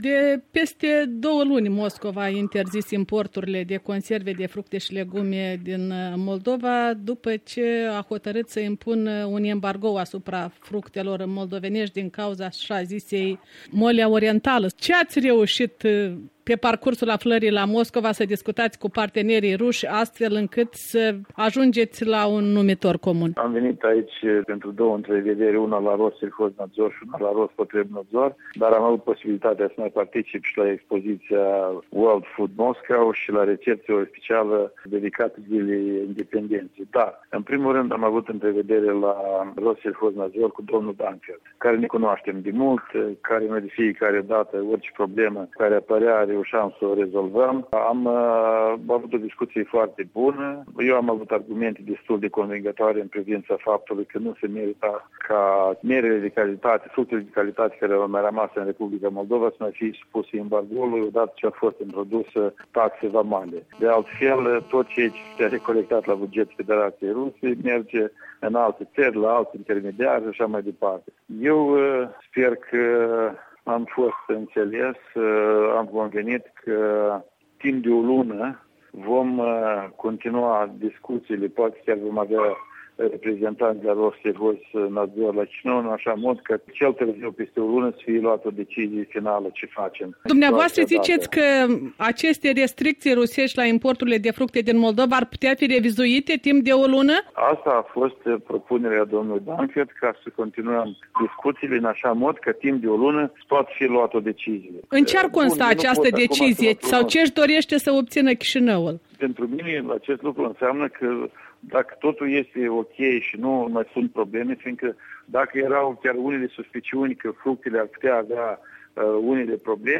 Ministrul agriculturii Vasile Bumacov, în dialog